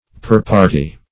Purparty \Pur"par`ty\, n. [OF. pourpartie; pour for + partie a